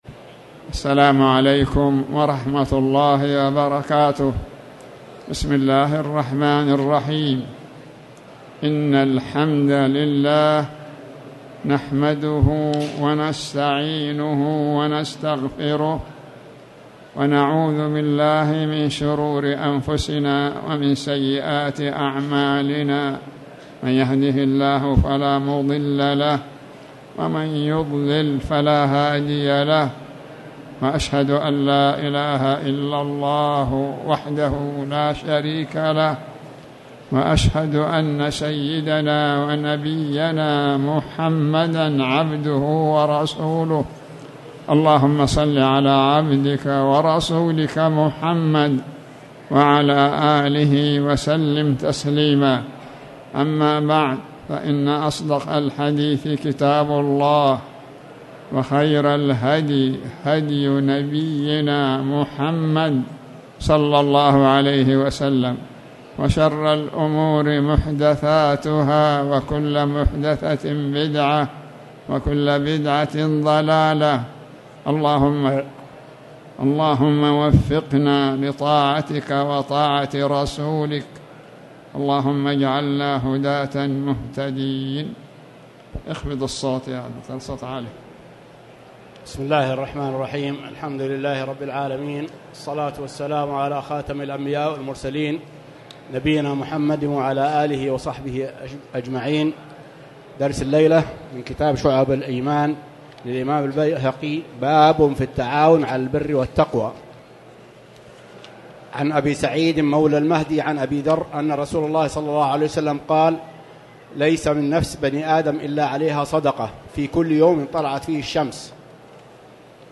تاريخ النشر ١٥ شوال ١٤٣٨ هـ المكان: المسجد الحرام الشيخ